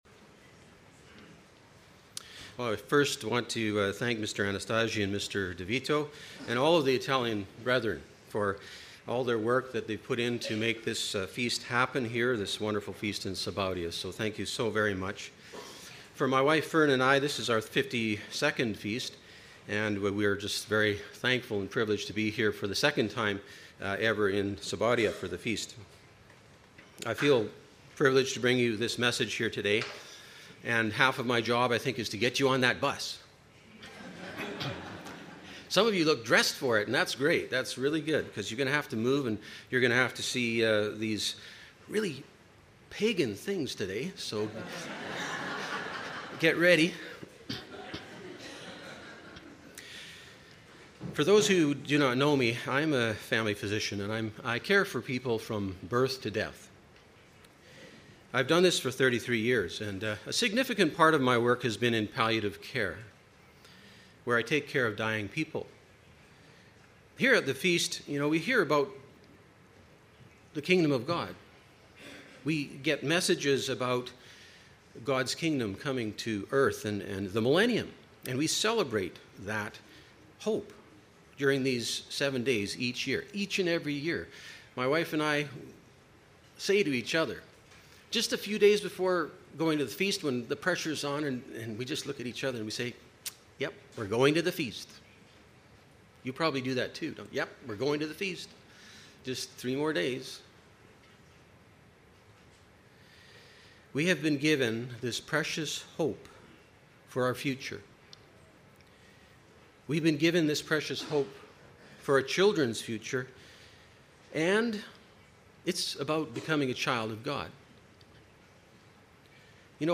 Sermone